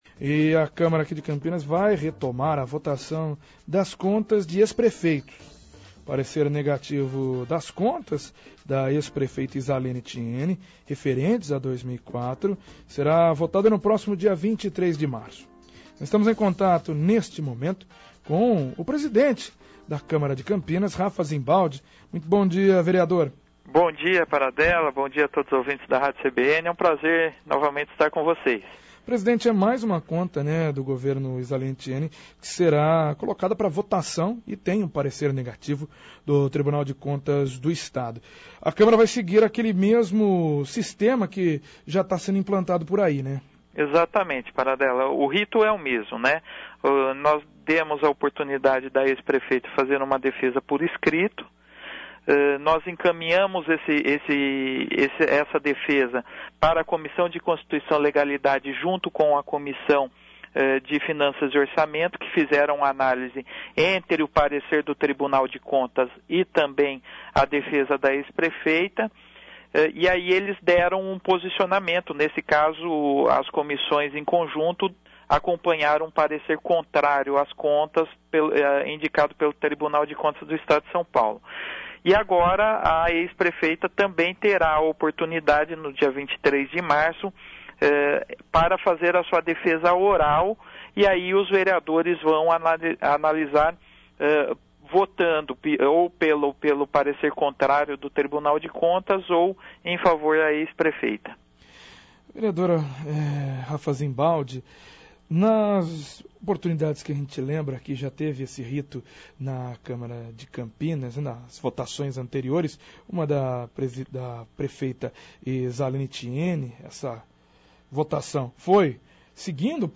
Presidente da Câmara Rafa Zimbaldi fala sobre a retomada da votação das contas de ex prefeitos de Campinas. - CBN Campinas 99,1 FM